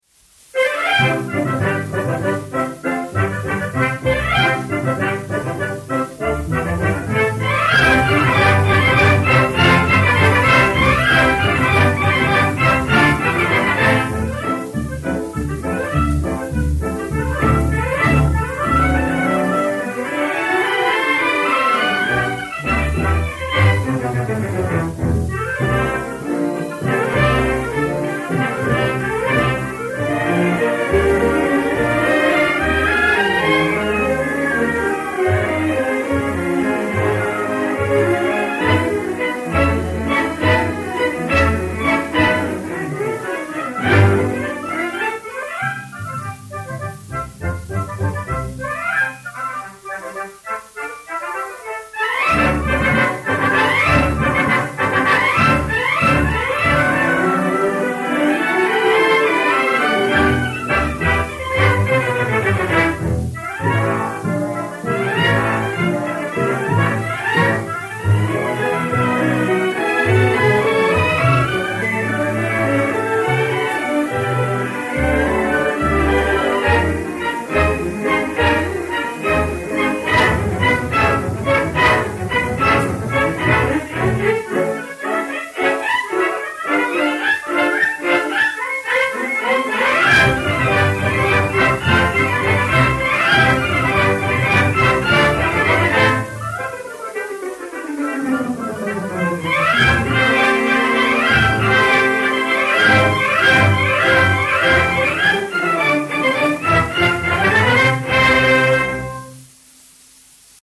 BALLET
Orchestre Symphonique dir Georges Hüe